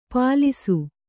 ♪ pālisu